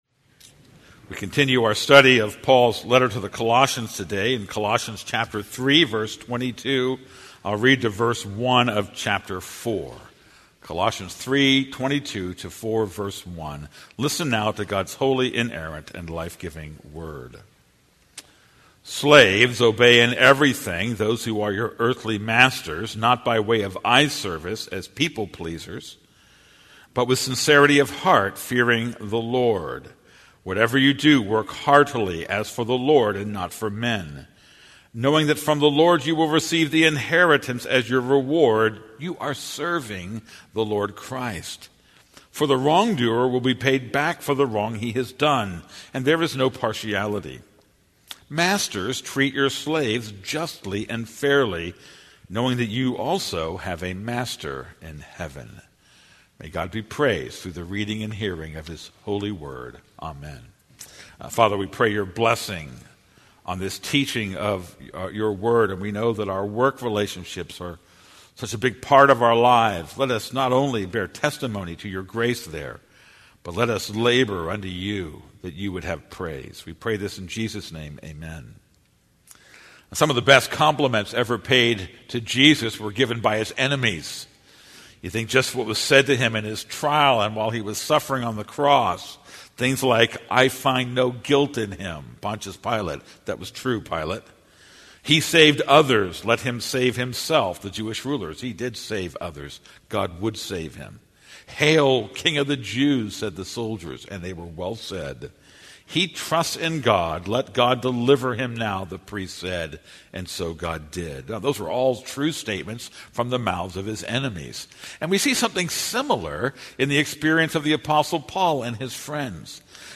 This is a sermon on Colossians 3:22-4:1.